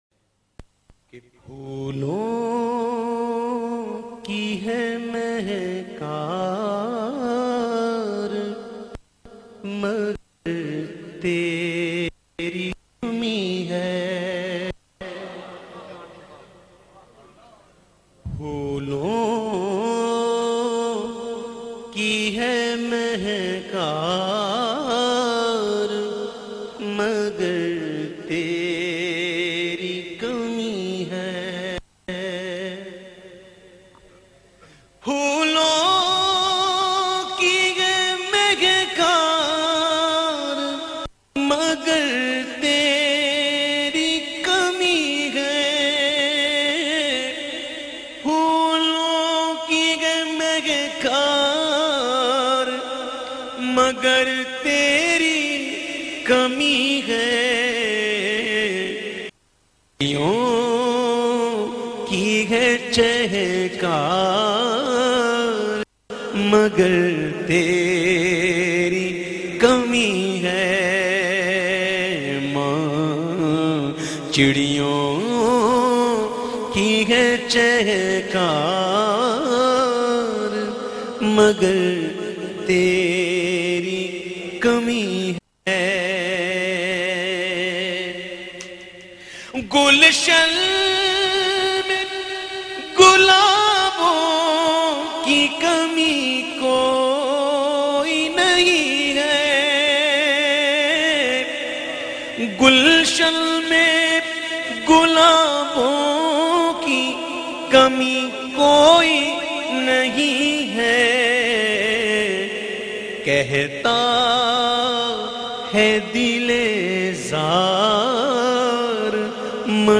Lyrics